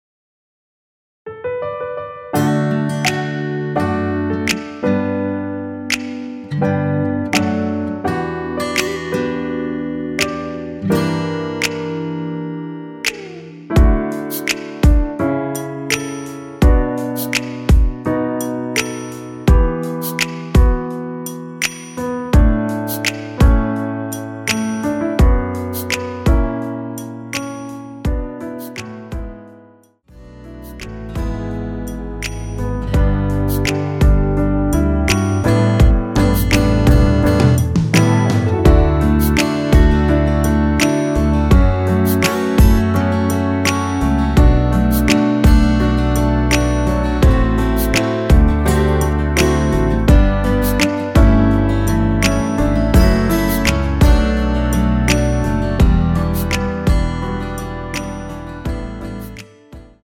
D
앞부분30초, 뒷부분30초씩 편집해서 올려 드리고 있습니다.